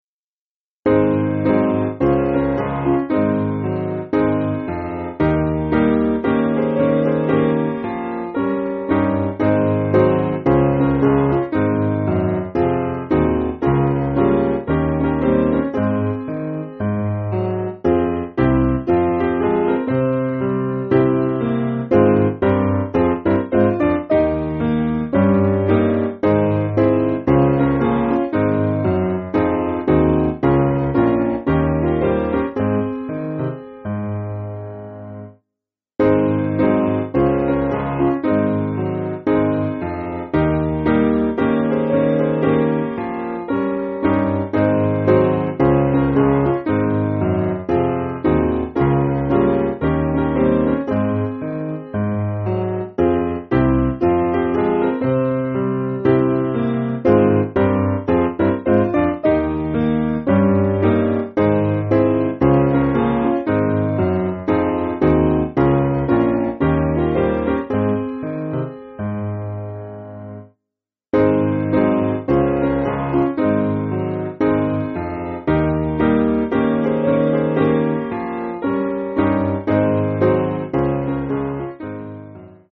Simple Piano